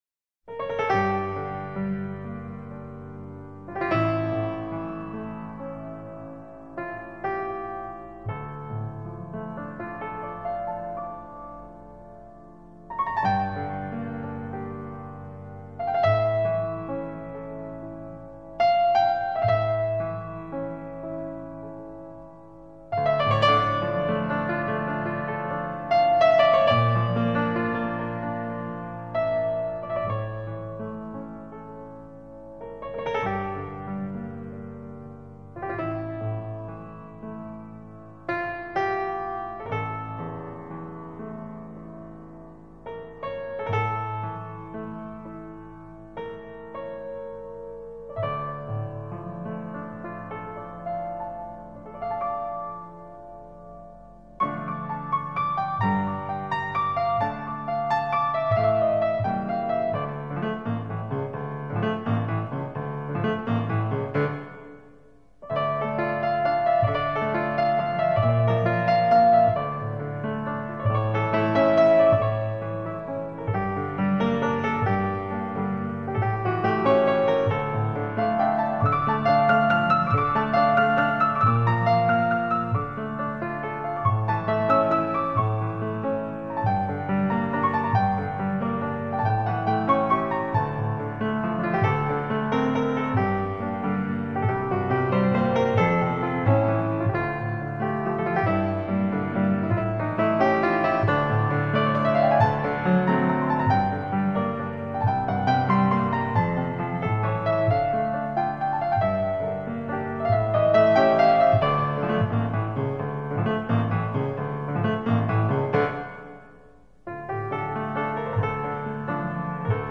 پیانو
قطعات بی‌کلام این آلبوم‌ها در دسته تکنوازی پیانو